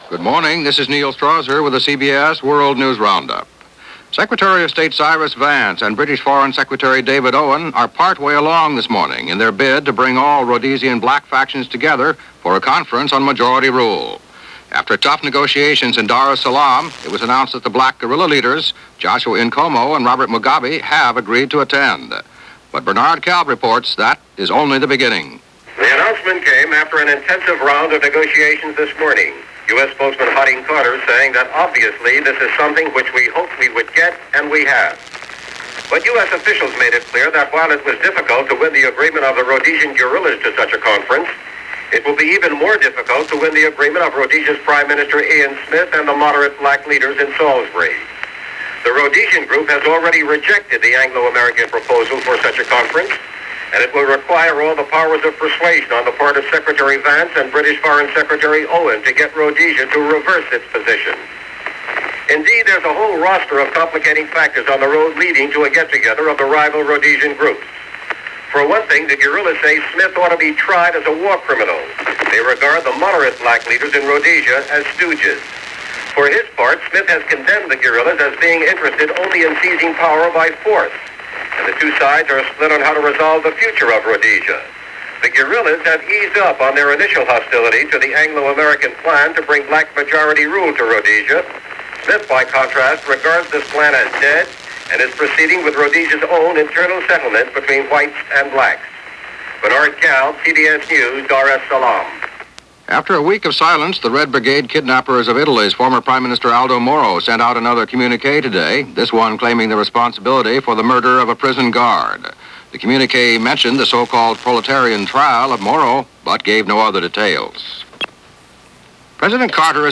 And along with the developing story on Rhodesia, that’s just a small slice of what happened, this April 15th in 1978 as reported by The CBS World News Roundup.